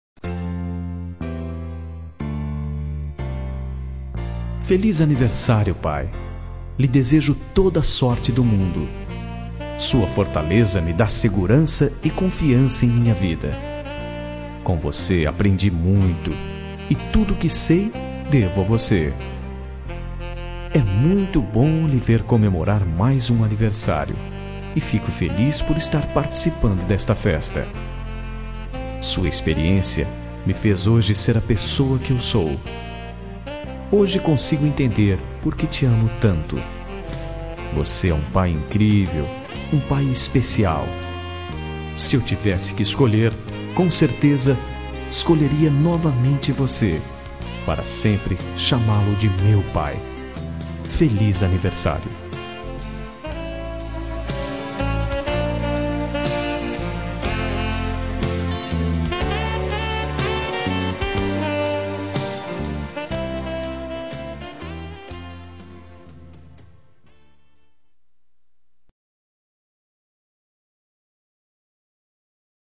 Telemensagem de Aniversário de Pai – Voz Masculina – Cód: 1494